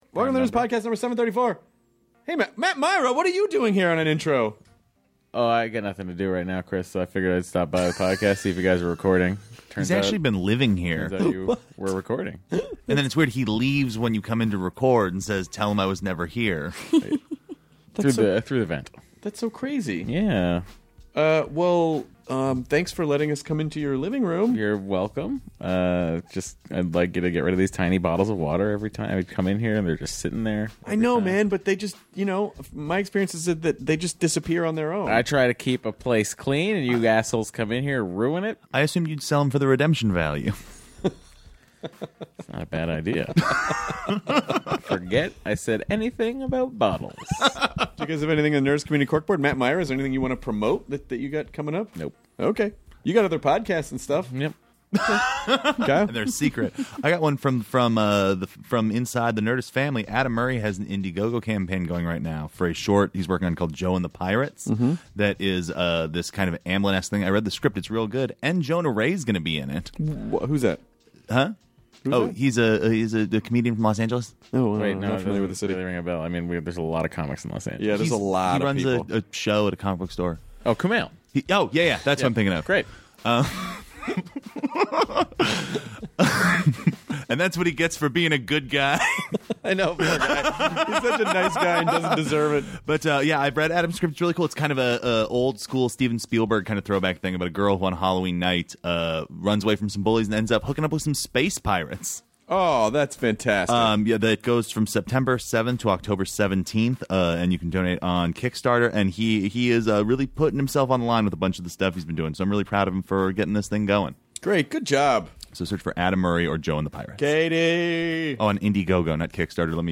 Brian Regan (comedian) chats with Chris about getting his start as a stand-up, how comedic preferences differ in people and how his comedy has influenced other comedians. He also gives a taste of what a dirty Brian Regan joke might sound like and talks about his upcoming live Comedy Central special on September 26th at 9pm!